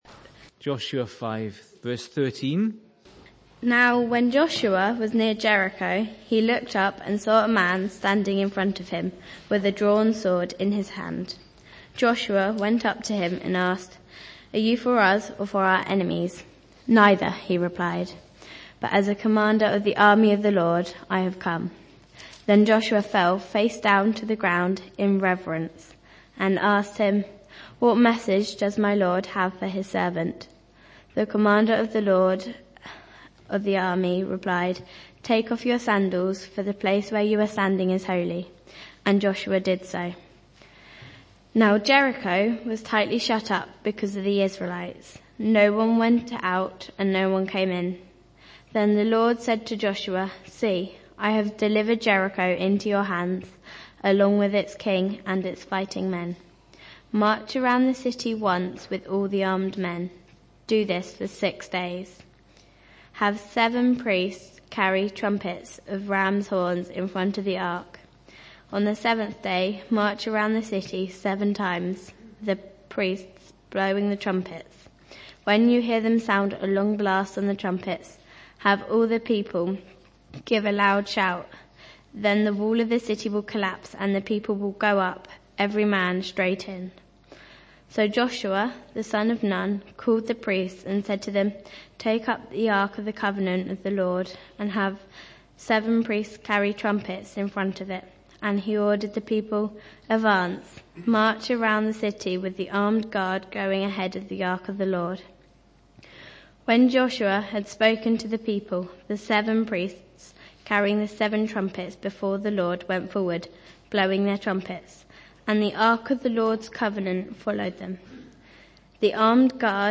Series: Sunday Mornings